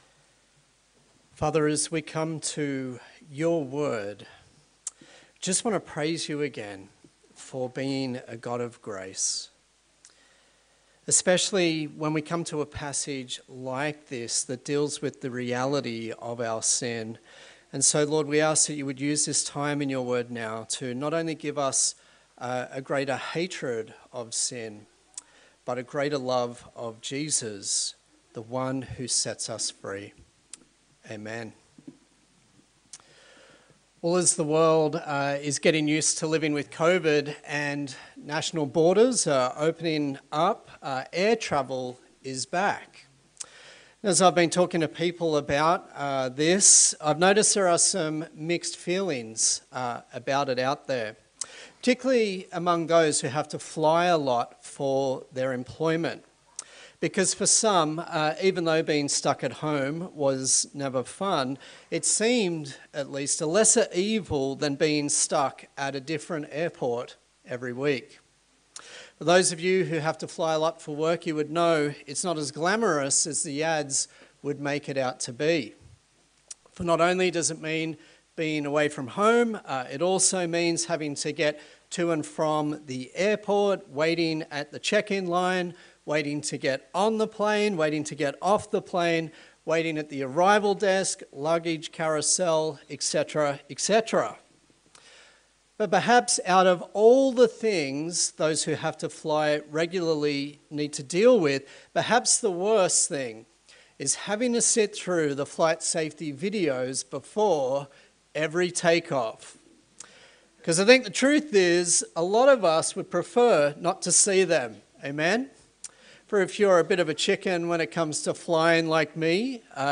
A sermon in the series on the book of Daniel
Daniel Passage: Daniel 9 Service Type: Sunday Service